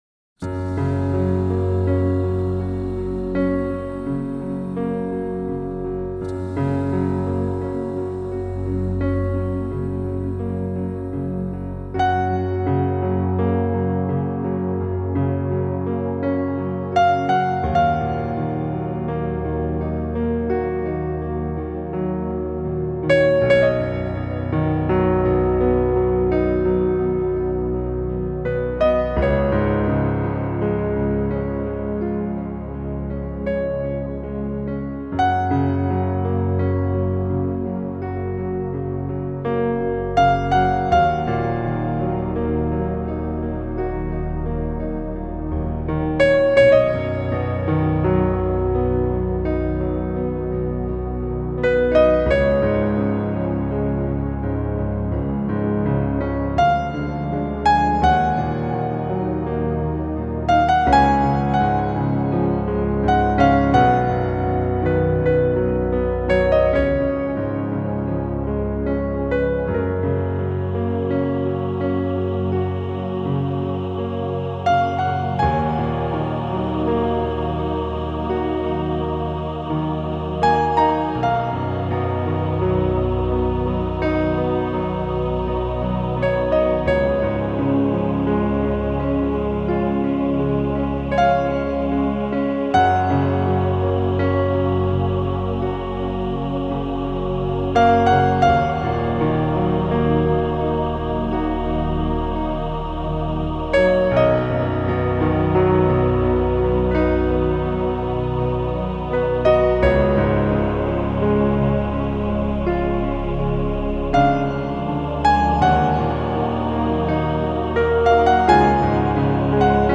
Жанр: New Age, Meditative